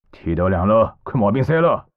c02_11肉铺敲门_1_fx.wav